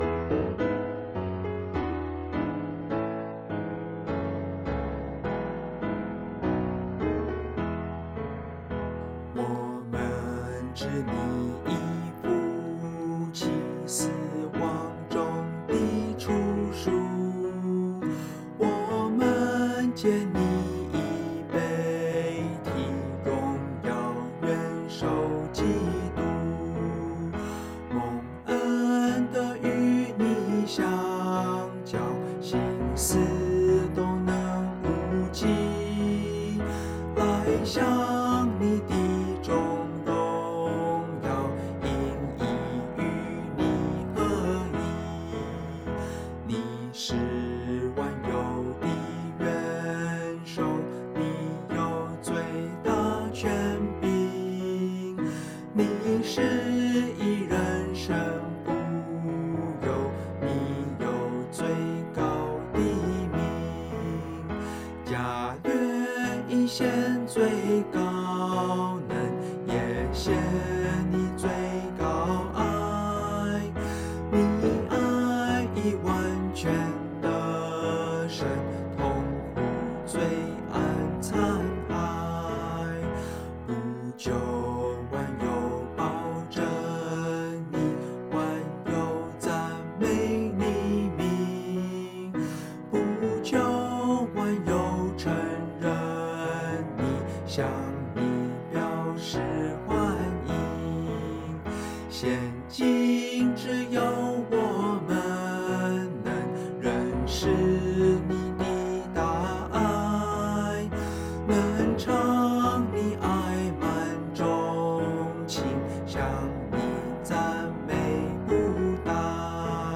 G大調